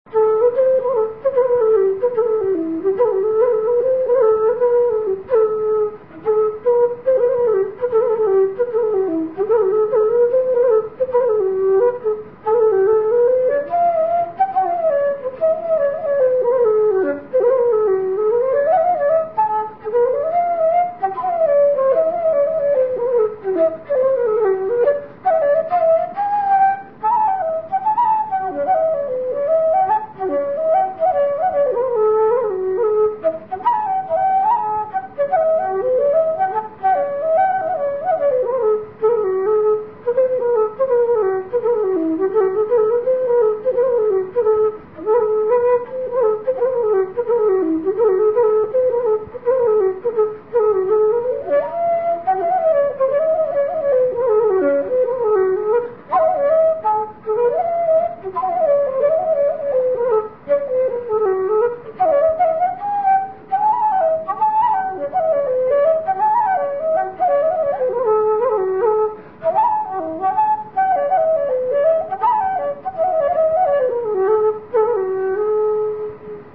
Here are a few short folk melodies I have composed.
A tip of the hat to Mister Van Morrison, more or less in the Celtic tradition.